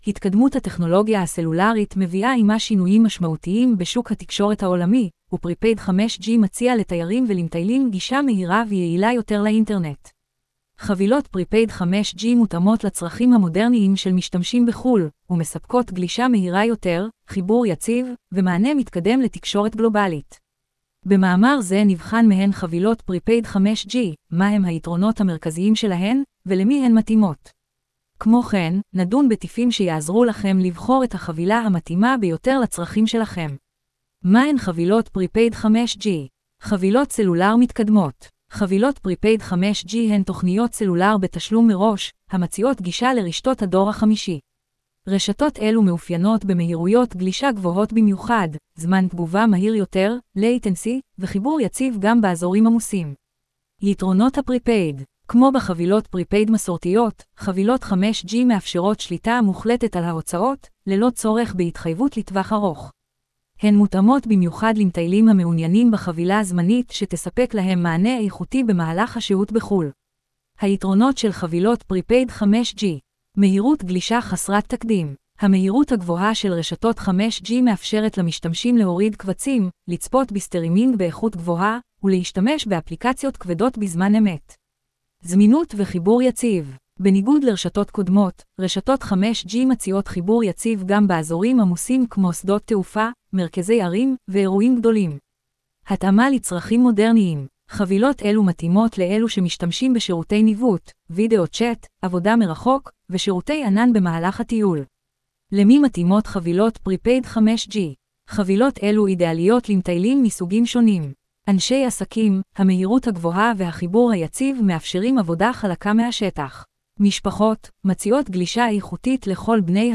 קובת-אודיו-הקראת-מאמר-חבילות-פריפייד-5G-–-הדור-הבא-של-הסלולר-בחו-ל.wav